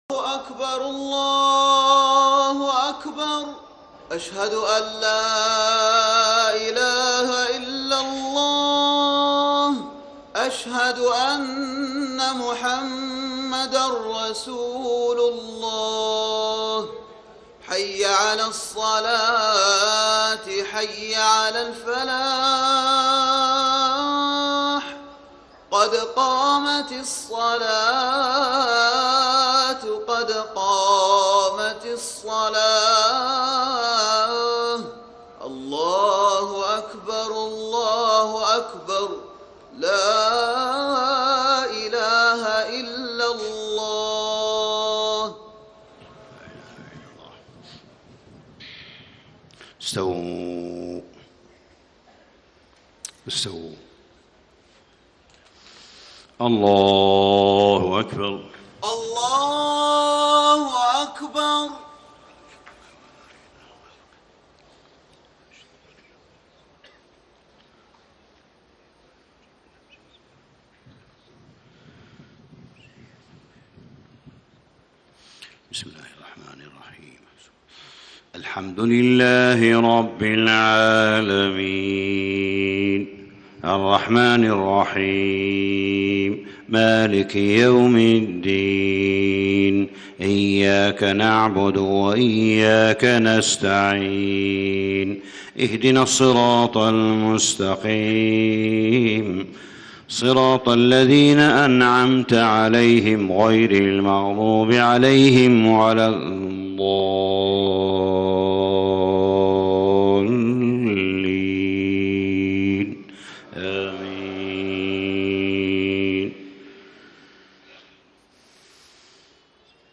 صلاة الفجر 9 - 7 - 1435هـ من سورة النساء > 1435 🕋 > الفروض - تلاوات الحرمين